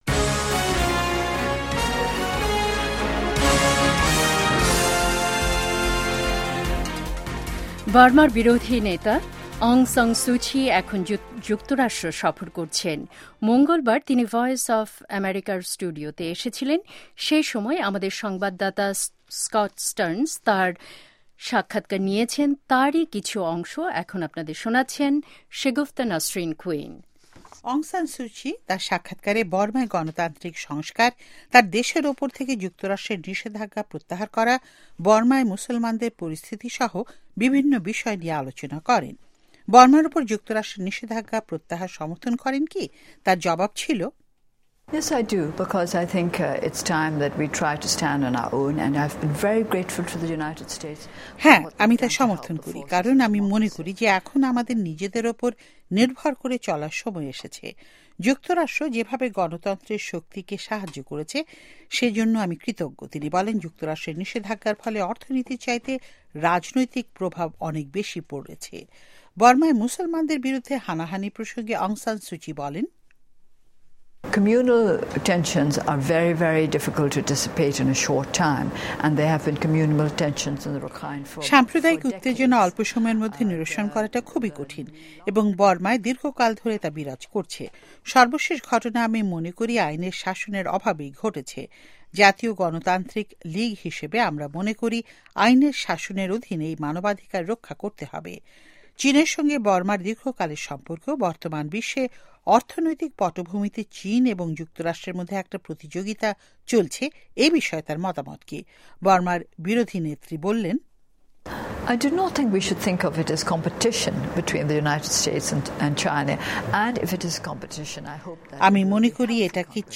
অং সান সূ চির সাক্ষাতকার